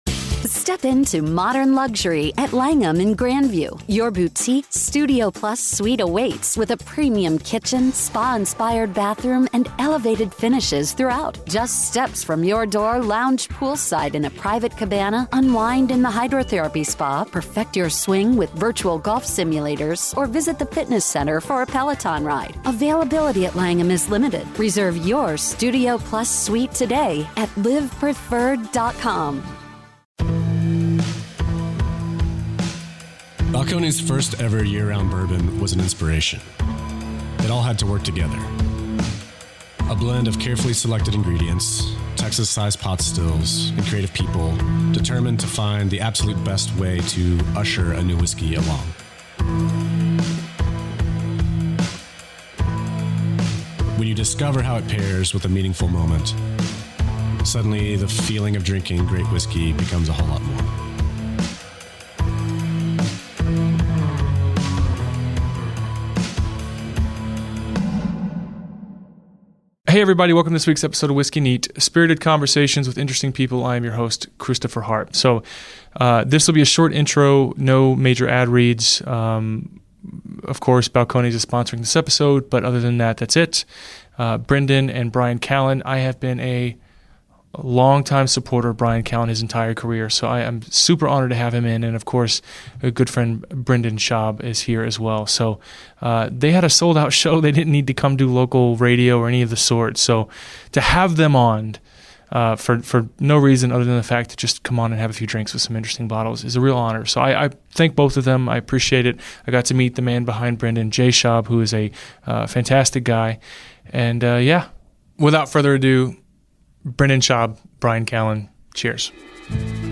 Whiskey Neat is a Radio show on iTunes and ESPN 97.5 FM in Houston and is brought to you every week by the following sponsors.